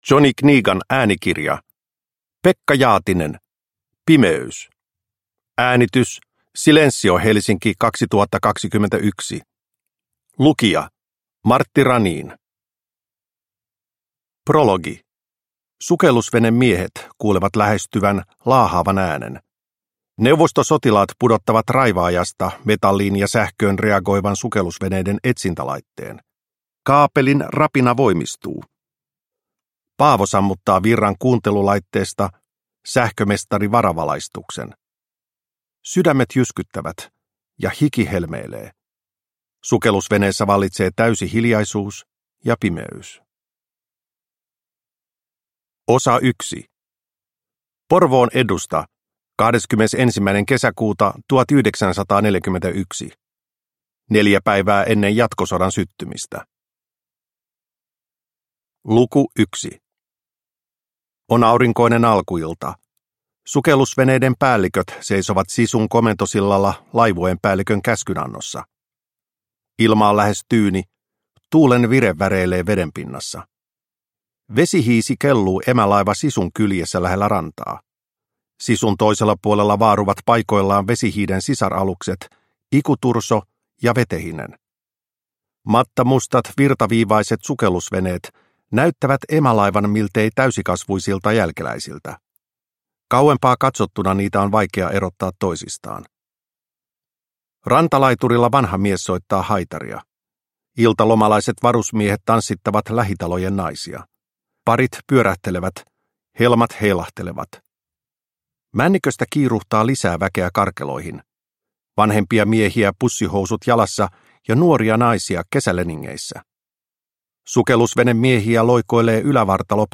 Pimeys – Ljudbok